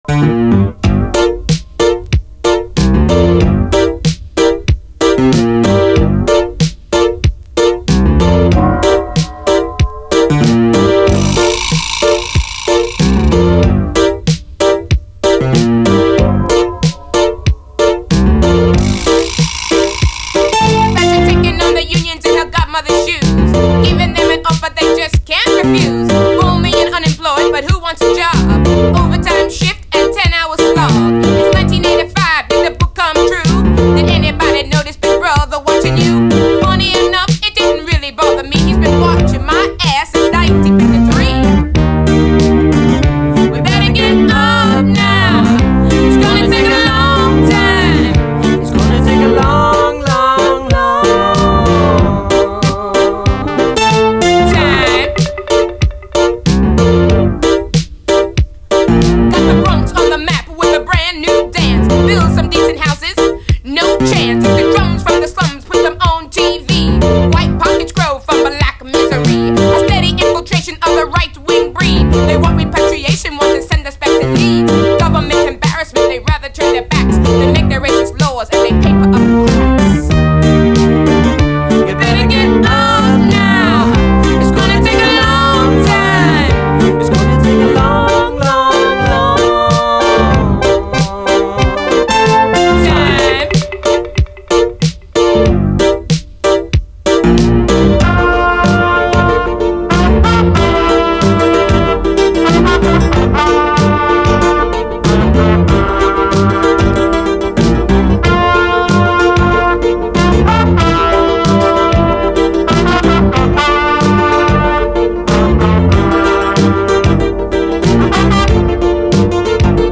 AFRO, 80's～ ROCK, REGGAE, WORLD, ROCK
黒人女性＆白人男性の２人組でほとんどの演奏を多重録音、その手作り感溢れるスカスカの音像が魅力です！
ジャンル分け不能、全く一筋縄ではいかないサウンド！